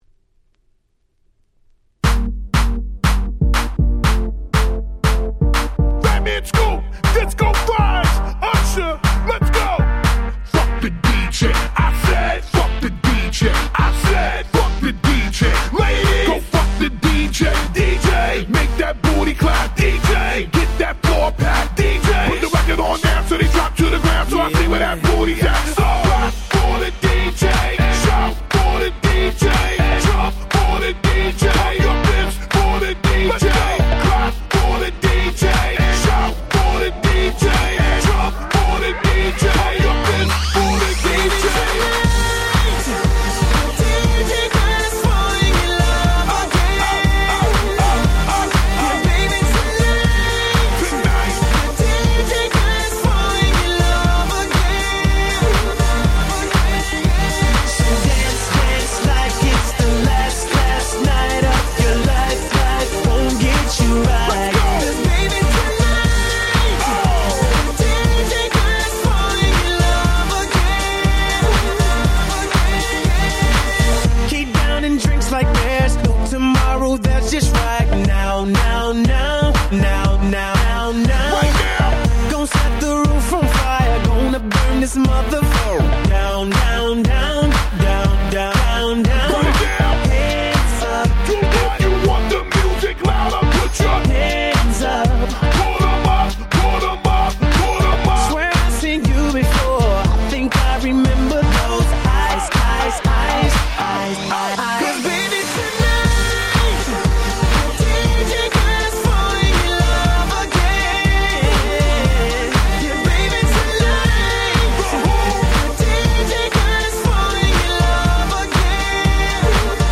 10' Super Hit R&B !!